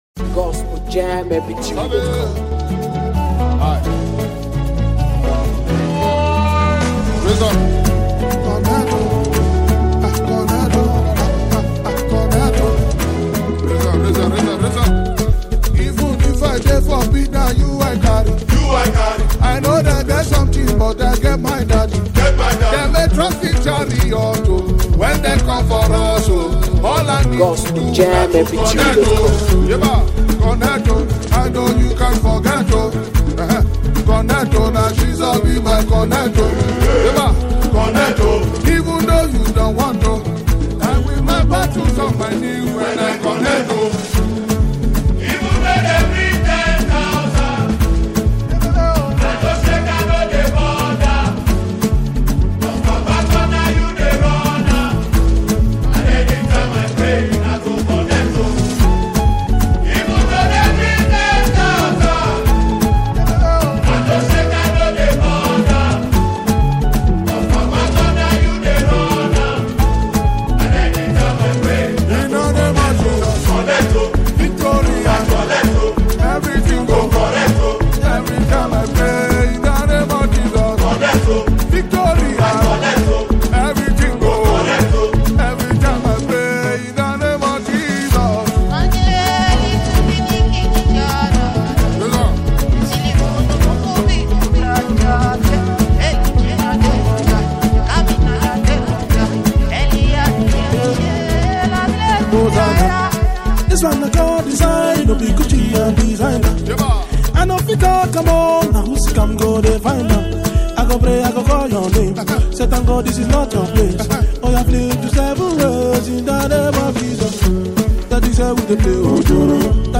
Featuring soul-stirring and energetic sounds